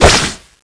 Index of /server/sound/weapons/tfa_cso/dreadnova
slash4.wav